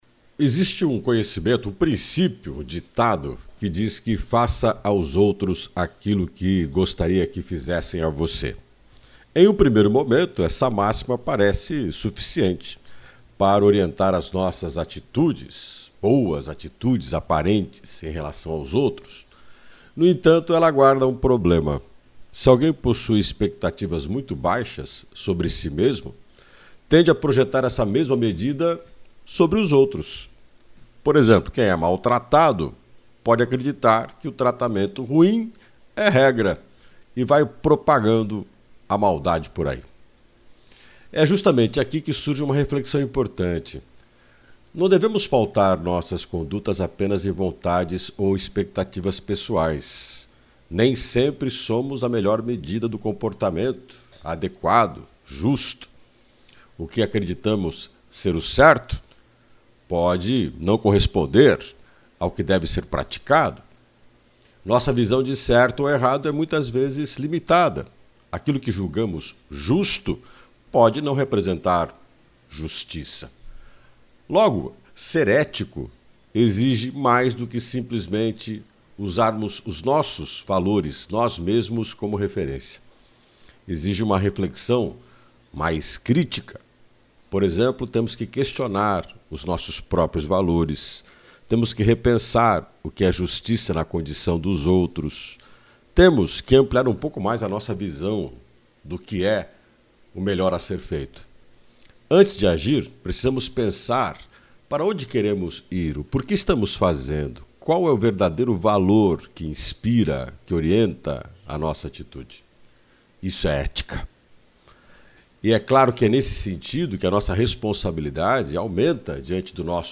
O comentário